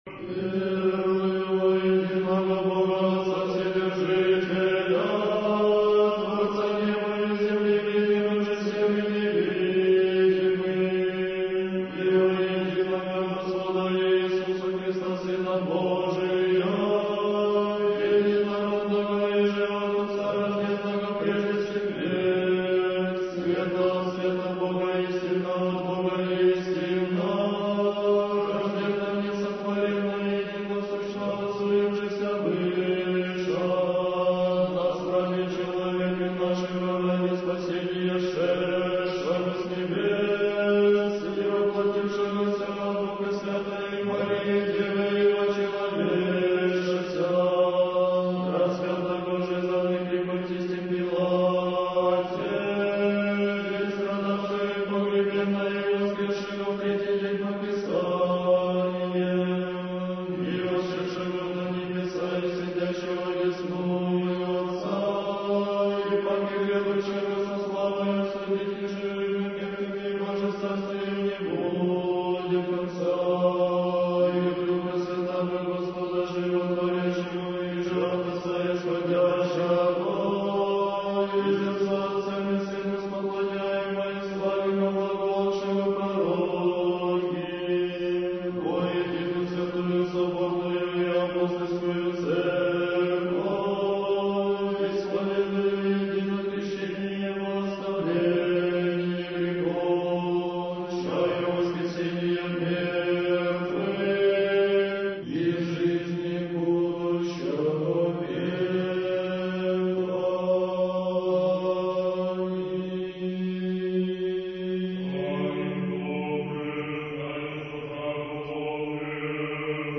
Архив mp3 / Духовная музыка / Русская / Хор Троице-Сергиевой Лавры под управлением архимандрита Матфея (Мормыля) / Литургия в Черниговско-Гефсиманском скиту /